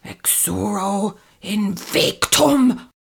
mandrake fvttdata/Data/modules/psfx/library/incantations/older-female/fire-spells/exuro-invictum
exuro-invictum-commanding.ogg